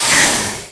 c_wnagaf_hit1.wav